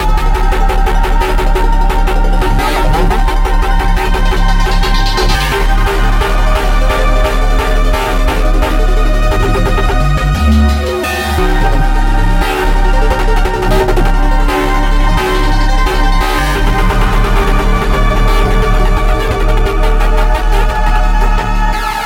标签： 电子 贝斯 合成器 循环 东方 配音 寒意 低速 器乐 环境 电子
声道立体声